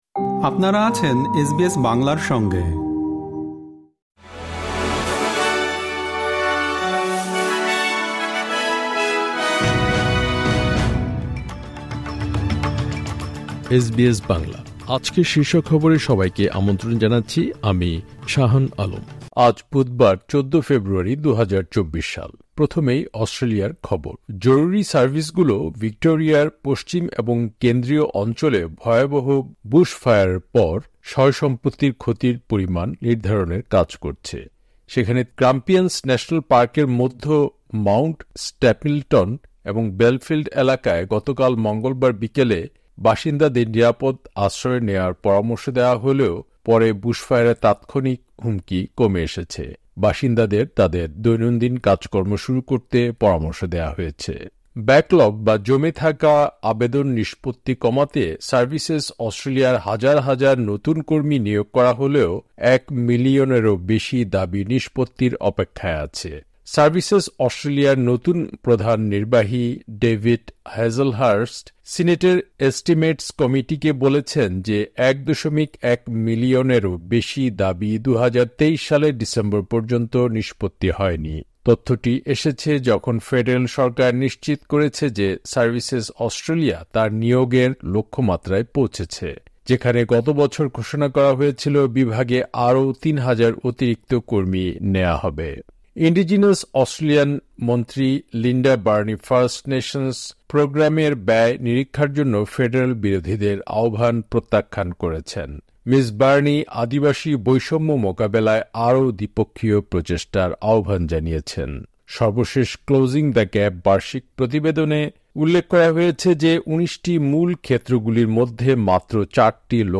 এসবিএস বাংলা শীর্ষ খবর: ১৪ ফেব্রুয়ারি, ২০২৪